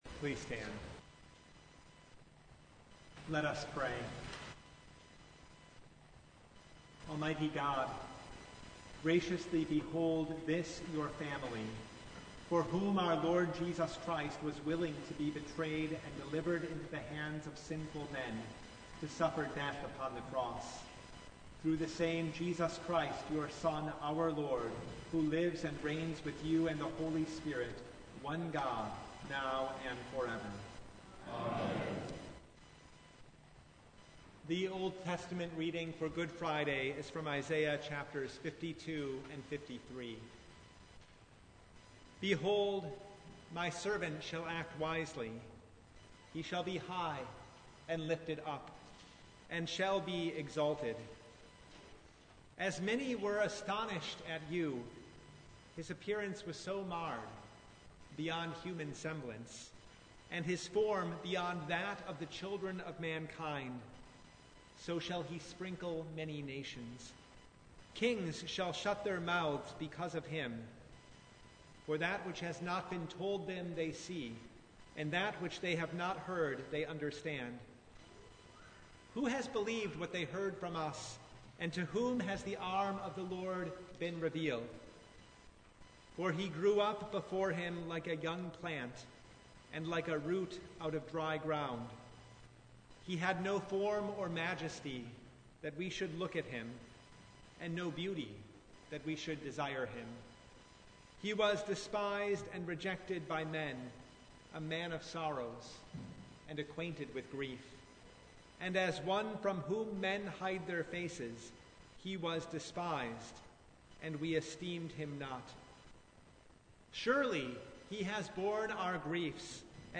Service Type: Good Friday Noon
Full Service